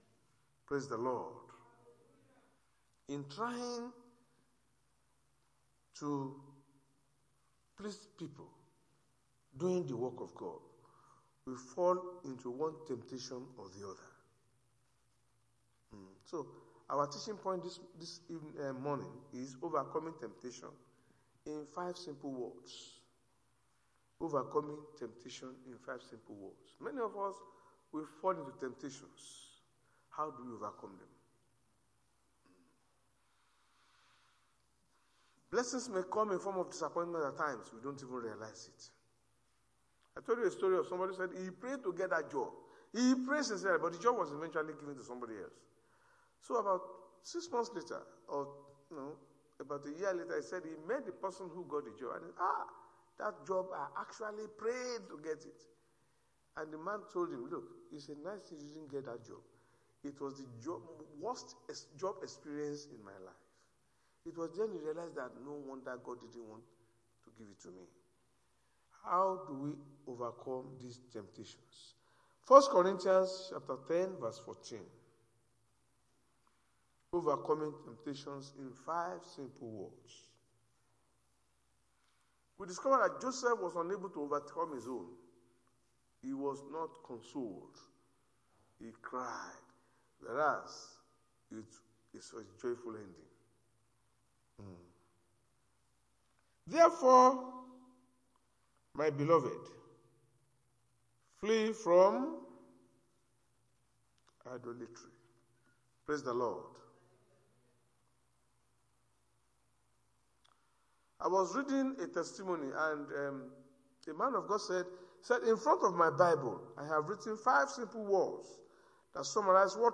Sunday Sermon: Overcoming Temptation In 5 Simple Steps
Service Type: Sunday Church Service